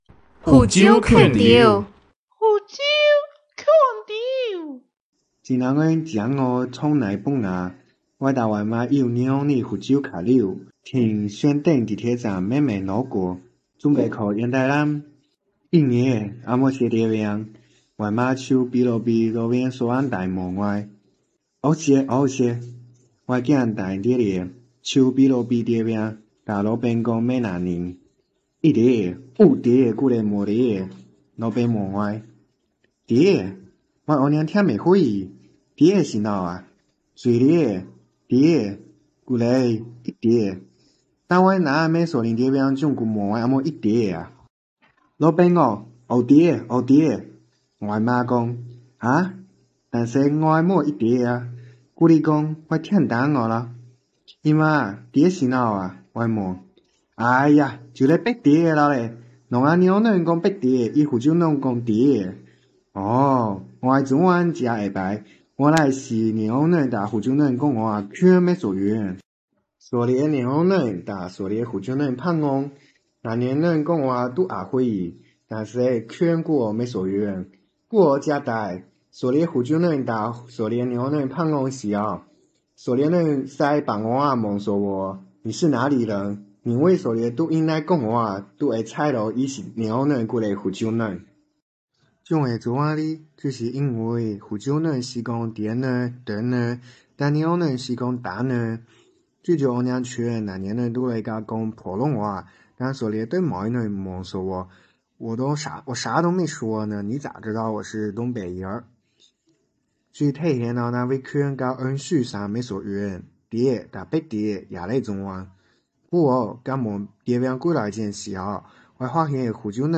连江腔（晚报） | 福州话资源网